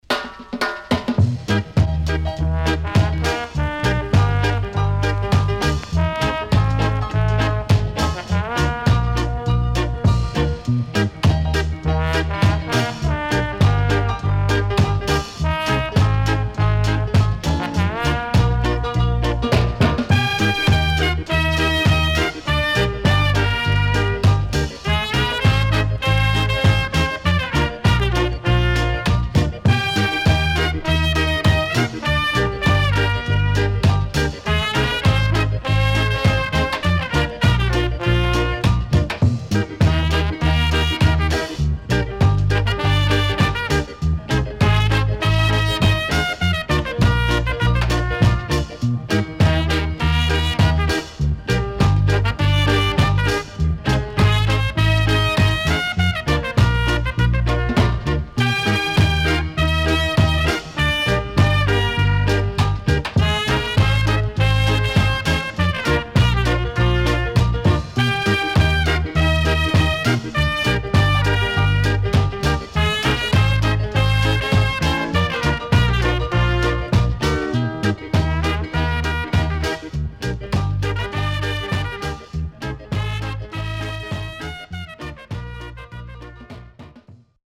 riddim
SIDE A:所々ノイズ入ります。